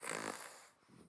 minecraft / sounds / mob / fox / sleep5.ogg
sleep5.ogg